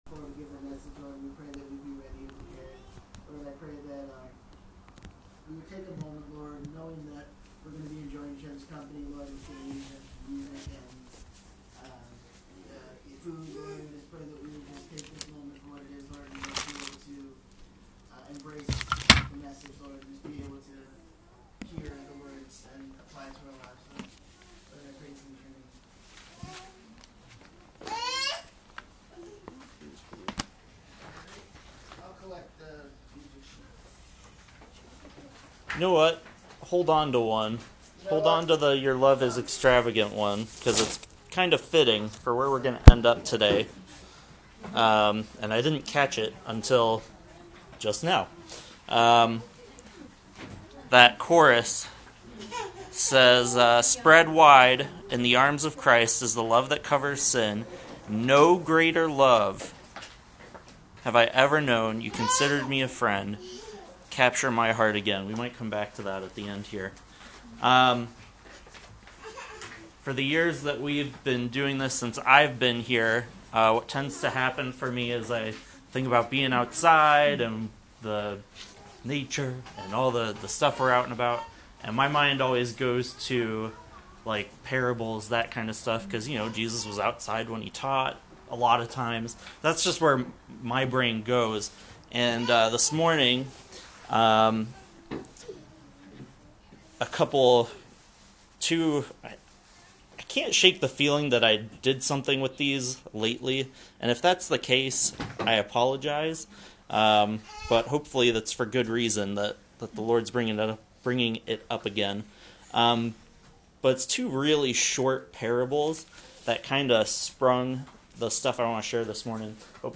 A brief message from our Church Picnic